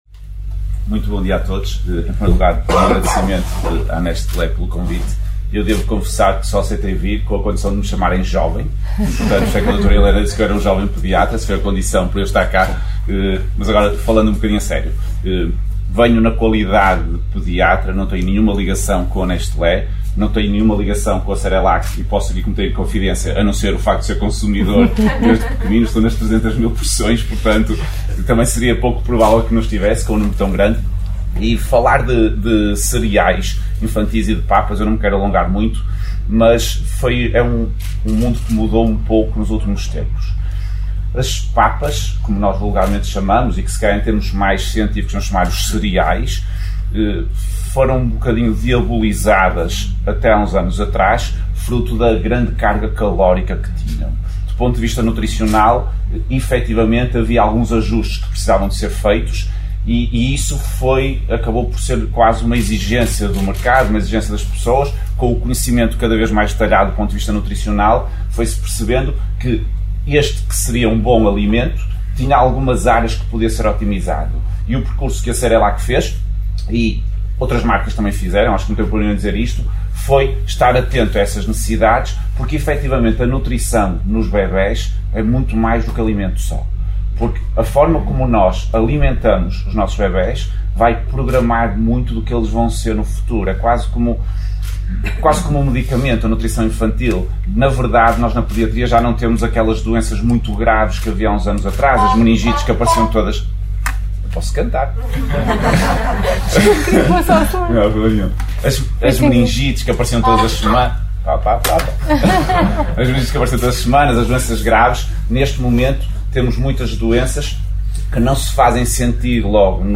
Após estas introduções, que pode ouvir na integra clicando nos players respetivos, a organização das celebrações levou os convidados a visitar parte das instalações fabris, num esforço de bem receber revestido de uma logística complexa, no intuito de garantir a segurança máxima, apanágio de toda a organização.